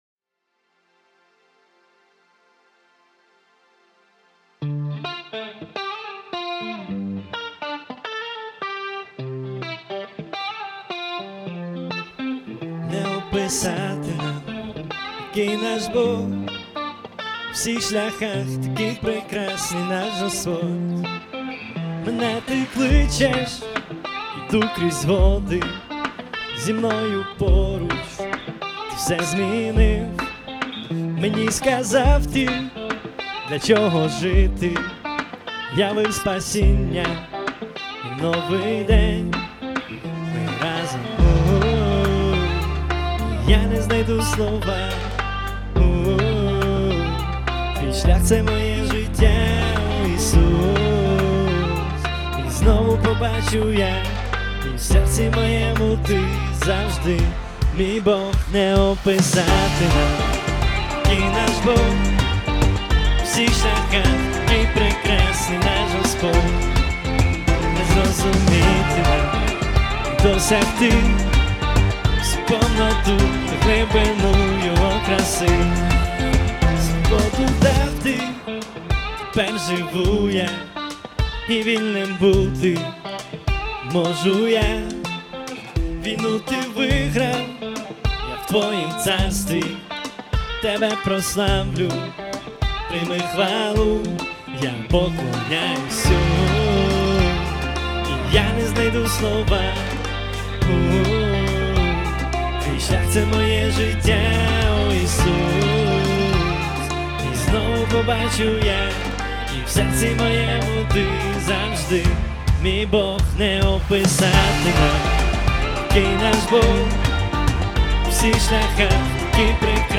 476 просмотров 293 прослушивания 0 скачиваний BPM: 105